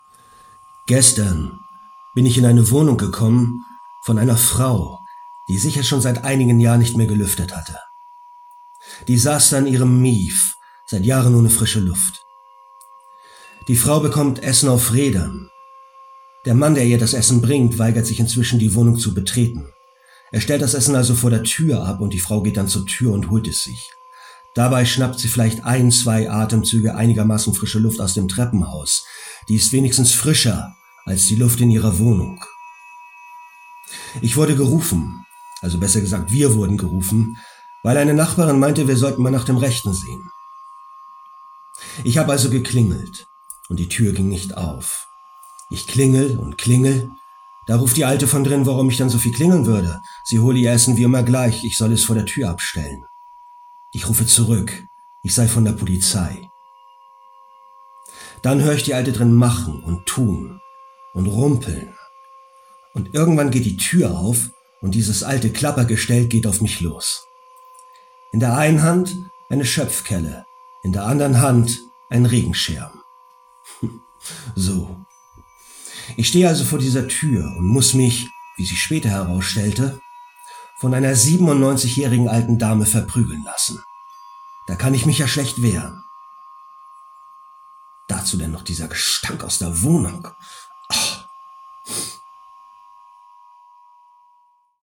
Commercial Demo Reel Deutsch